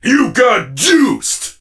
surge_kill_vo_03.ogg